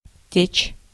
Ääntäminen
US
IPA : /ˈfloʊ/